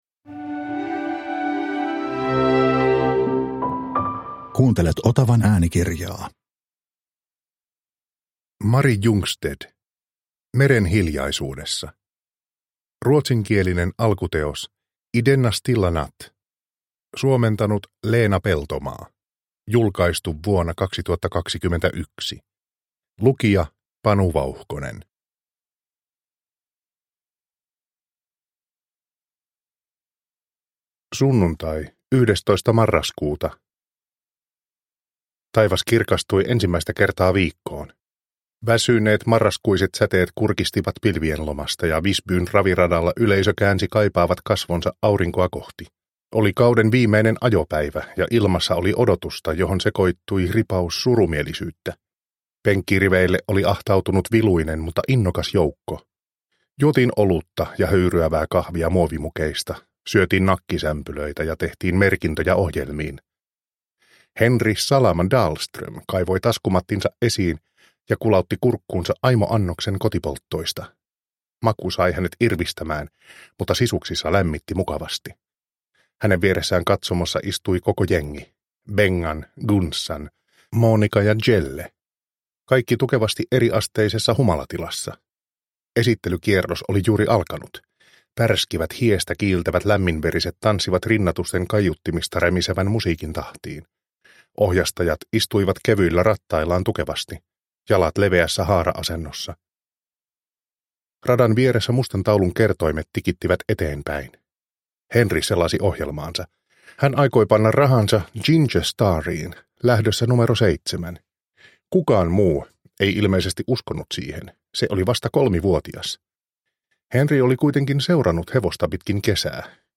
Meren hiljaisuudessa – Ljudbok – Laddas ner
Produkttyp: Digitala böcker